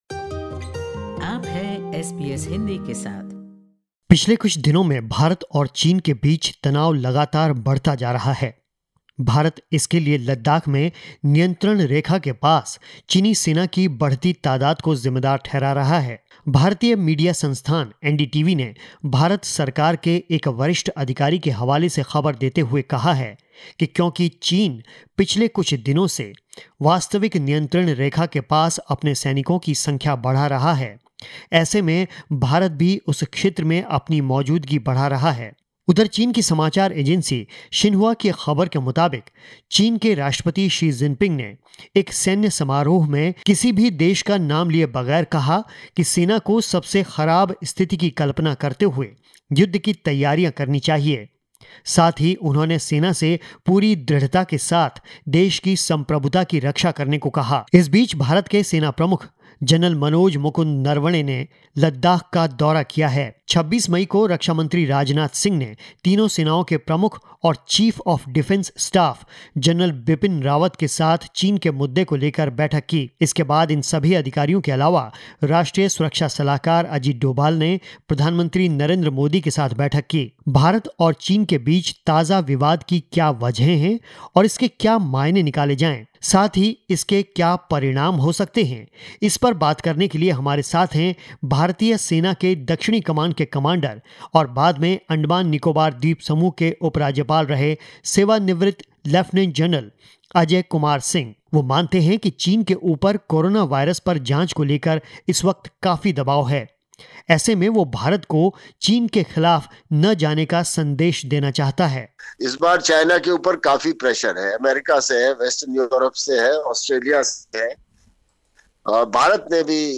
report_on_india-china_tension_podcast_0.mp3